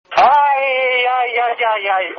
AUDIO: El piloto español se desespera cuando ve que no tiene batería en su Ferrari.